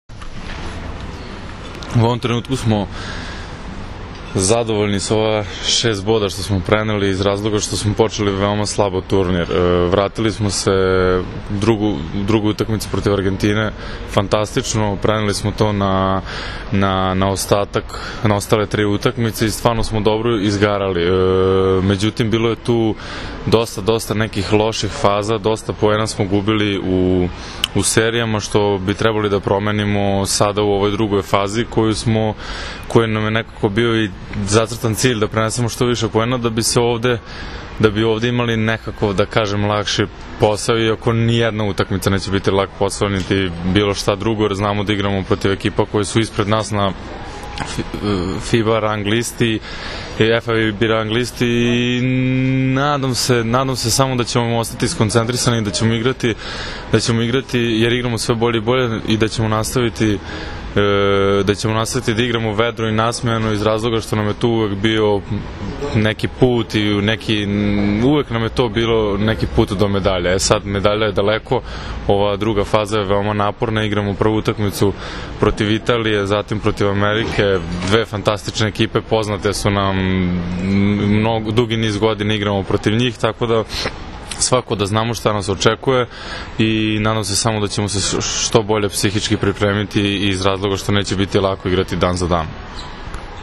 IZJAVA NIKOLE JOVOVIĆA